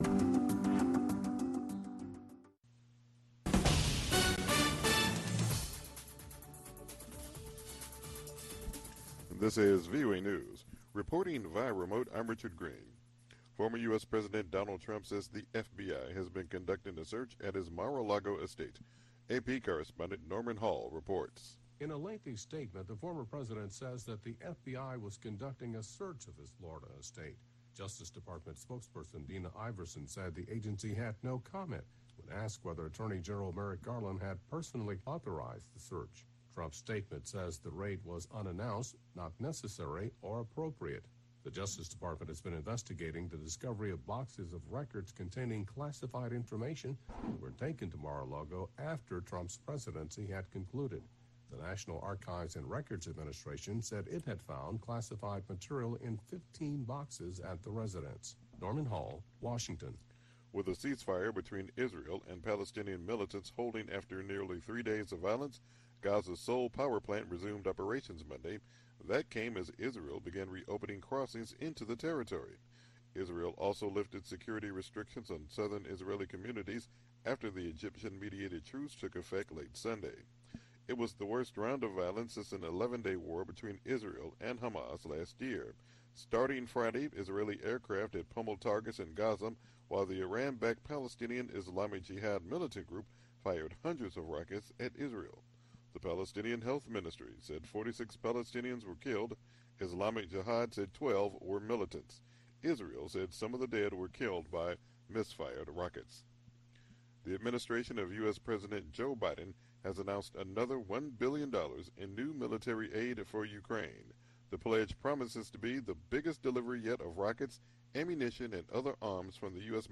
Voice of America: VOA Newscasts